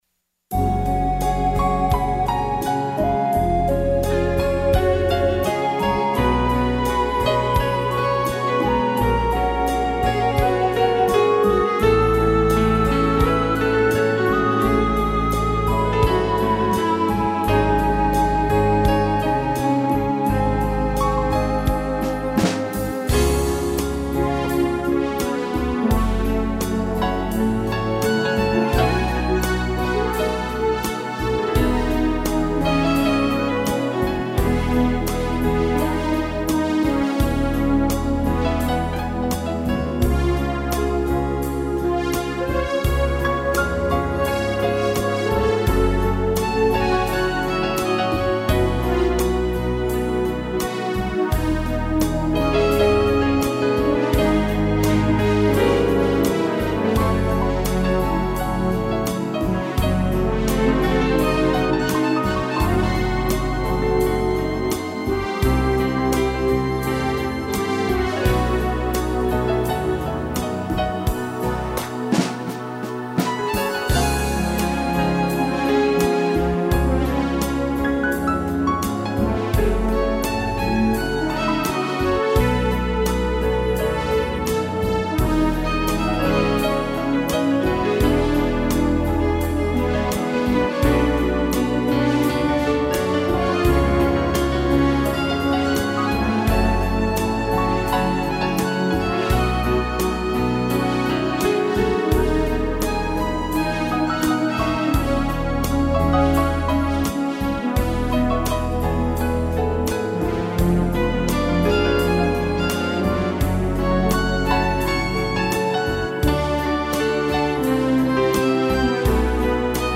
violino, cello e piano